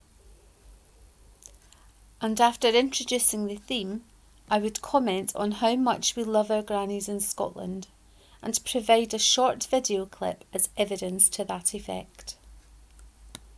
Shoving yer Grannie aff a bus 1 : reading of this post